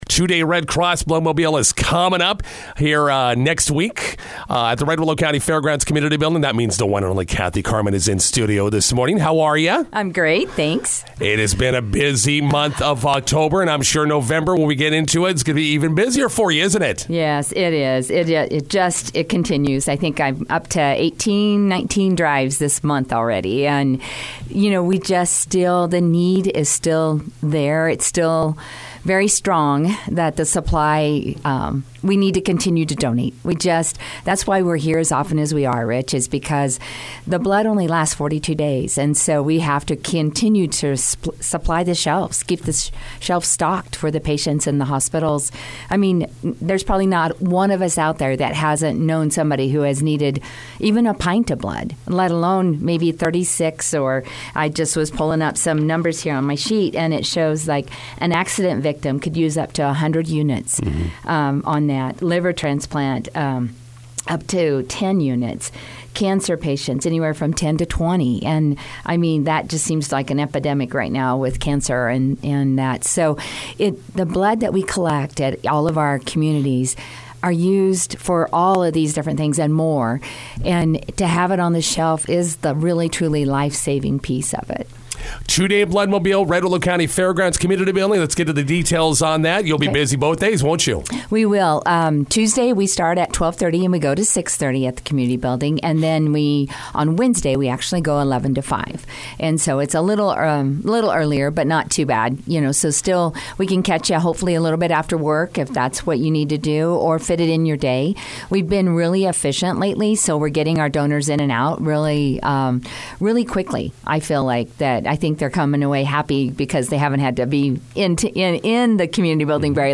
INTERVIEW: Red Cross Bloodmobile two-day event coming up on Oct. 28-29.